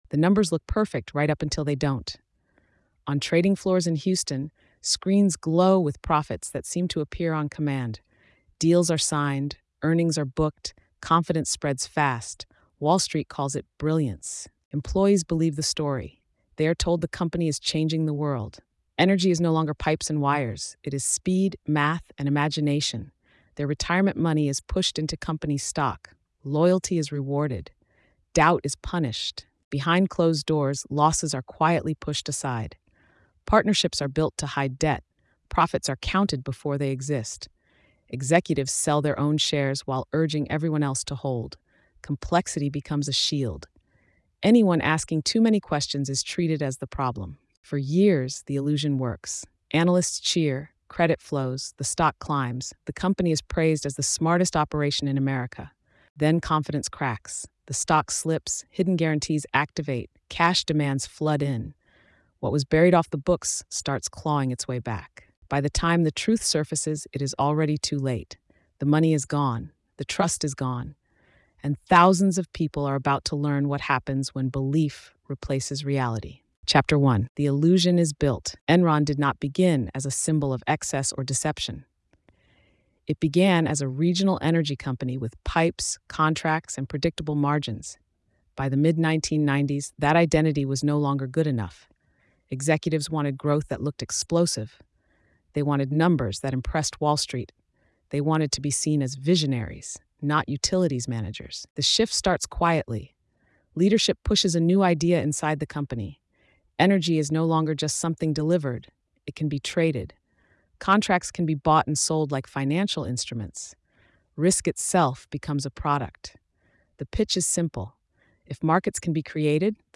This three-chapter investigative narrative examines the rise and collapse of Enron, tracing how a celebrated corporate innovator used aggressive accounting, hidden partnerships, and market manipulation to project unstoppable success. Told with a gritty, pressure-driven tone, the story centers on how confidence replaced reality, how employees were encouraged to risk everything on company stock, and how the system held together only as long as belief remained intact.